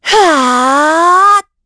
Isolet-Vox_Casting4_kr.wav